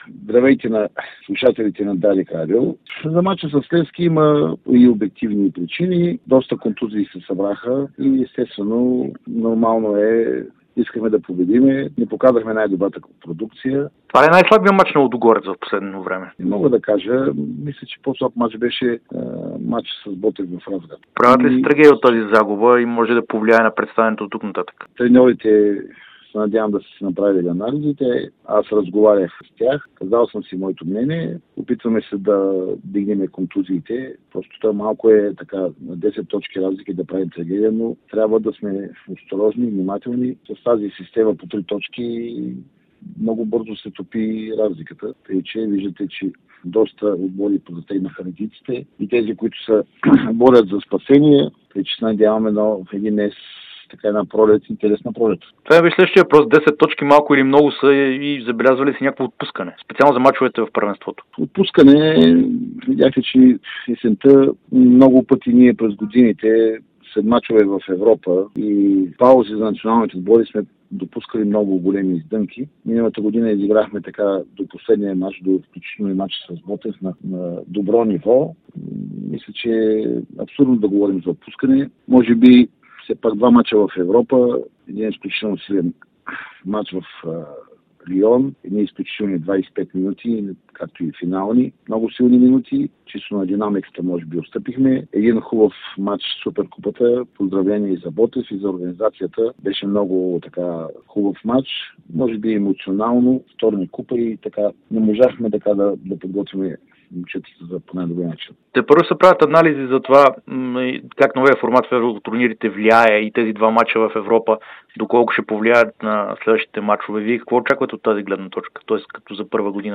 специално интервю